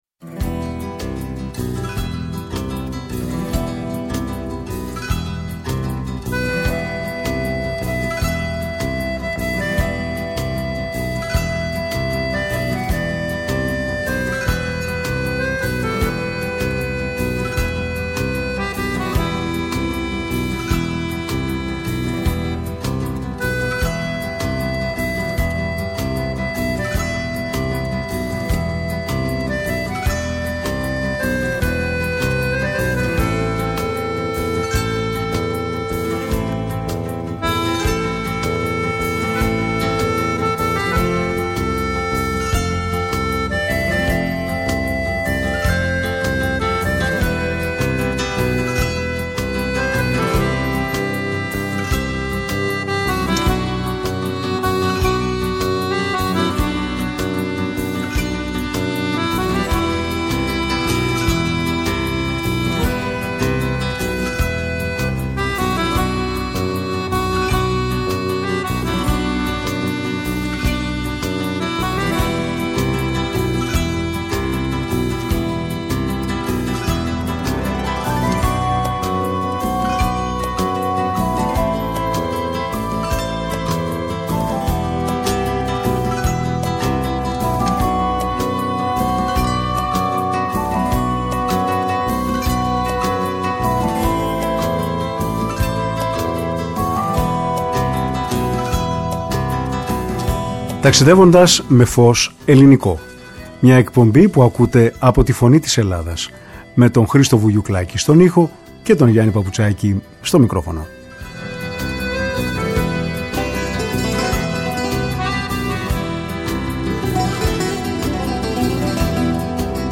Ταξιδεύοντας με φως ελληνικό”, όπου ακούστηκε ποίησή του και μελοποιημένοι στίχοι του